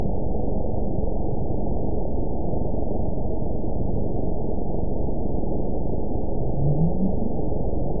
event 920488 date 03/27/24 time 22:46:49 GMT (1 year, 1 month ago) score 9.56 location TSS-AB01 detected by nrw target species NRW annotations +NRW Spectrogram: Frequency (kHz) vs. Time (s) audio not available .wav